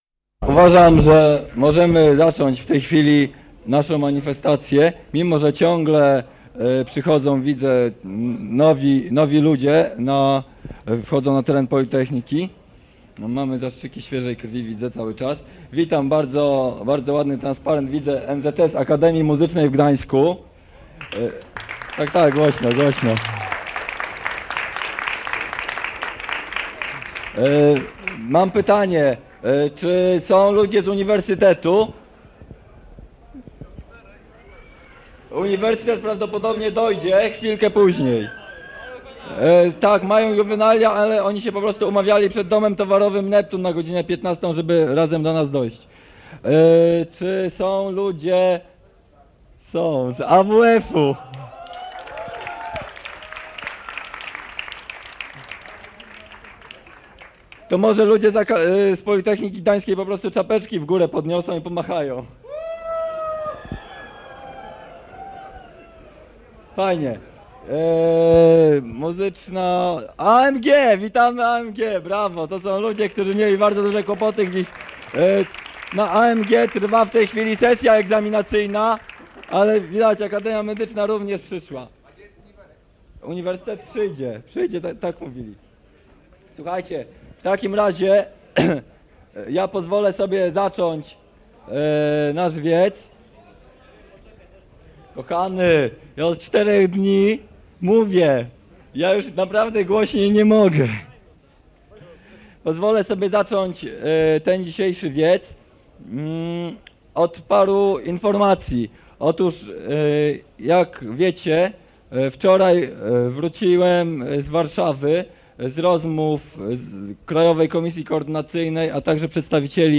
Wiec studencki przed Gmachem Głównym PG [dokument dźwiękowy] - ${res.getProperty('base.library.full')}